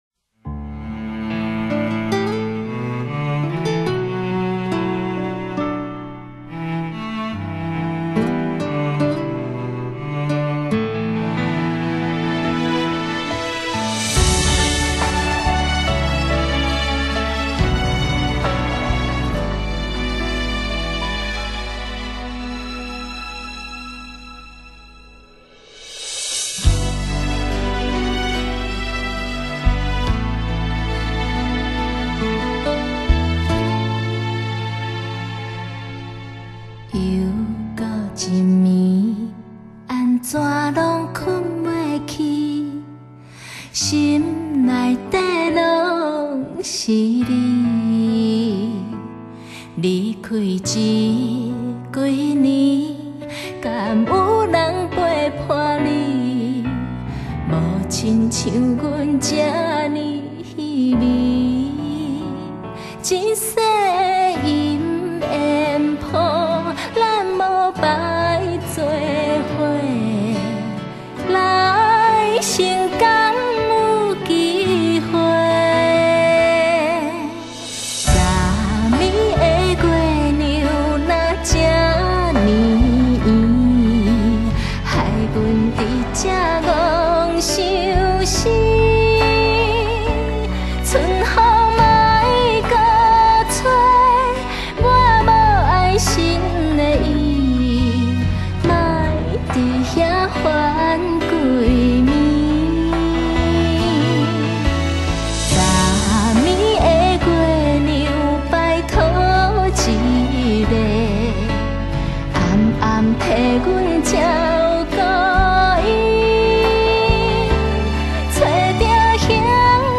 [专辑\合辑]
道地雅緻甜嗓百花齊放，香醇絕美歌謠極上盛開！